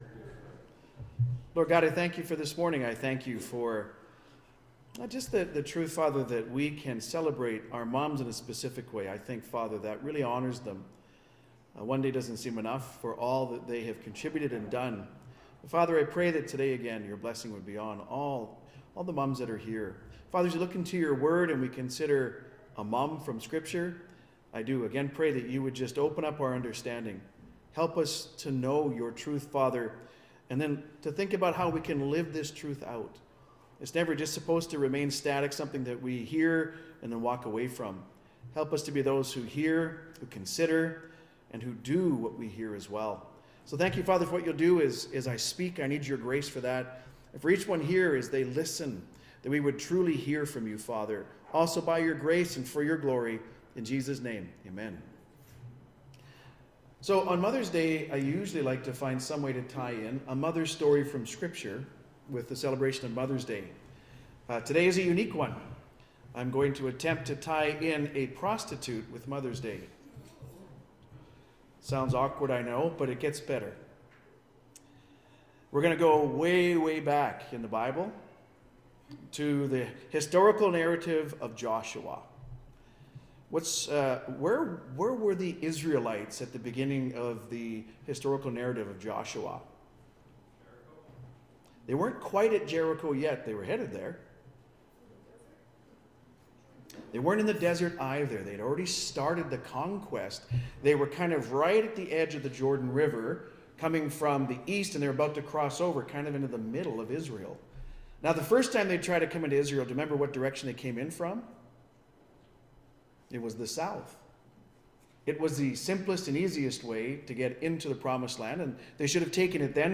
Mothers Day message: a prostitute’s testimony.